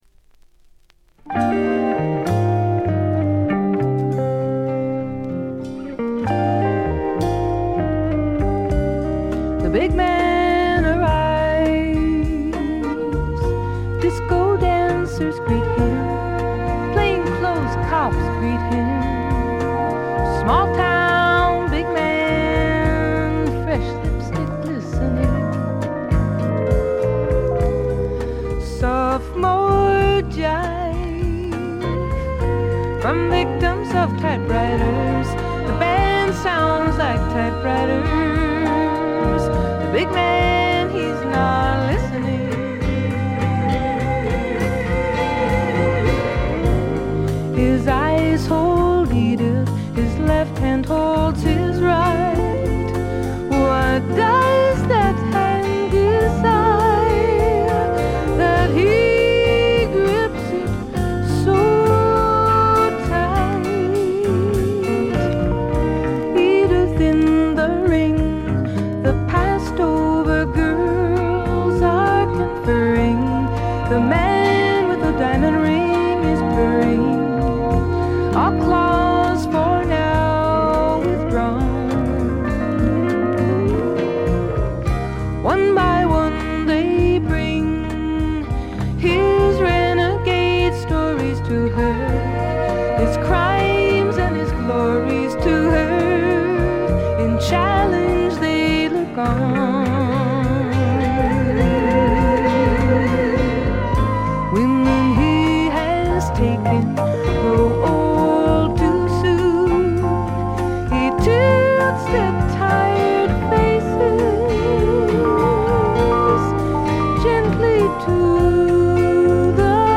微細なバックグラウンドノイズ程度でほとんどノイズ感無し。
ここからが本格的なジャズ／フュージョン路線ということでフォーキーぽさは完全になくなりました。
試聴曲は現品からの取り込み音源です。